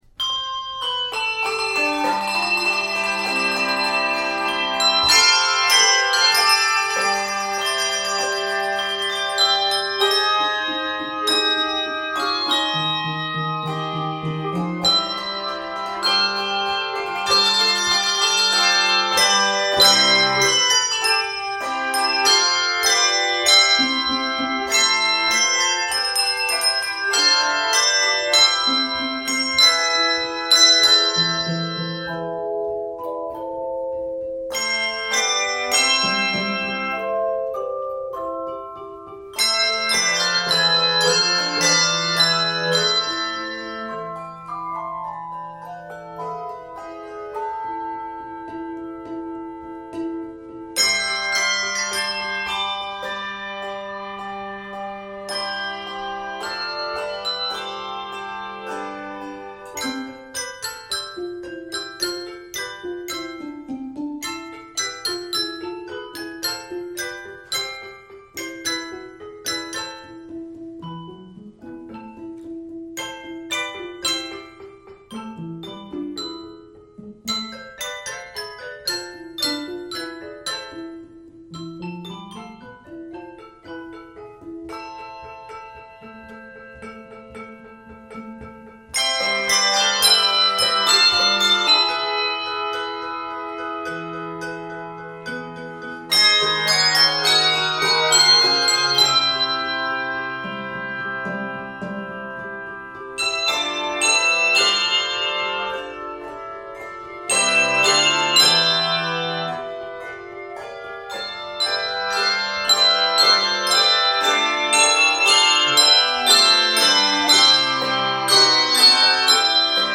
Traditional German Carol Arranger